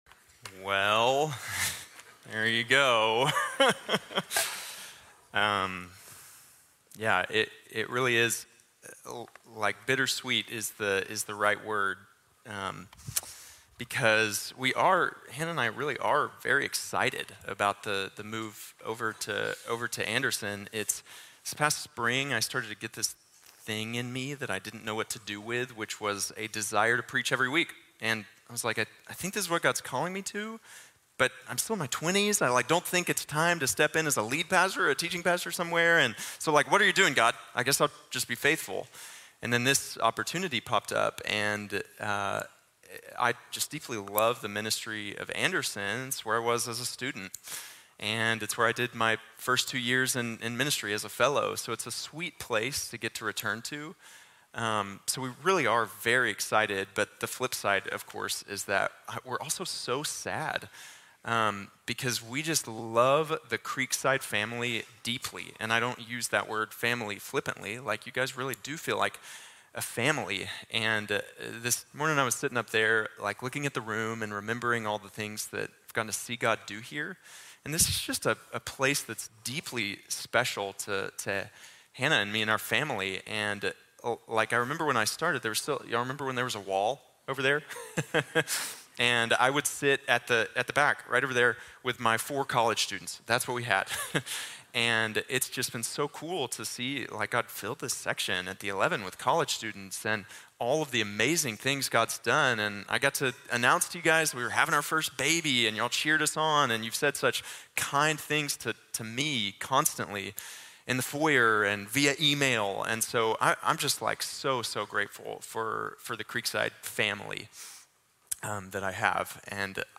Motivation for Ministry | Sermon | Grace Bible Church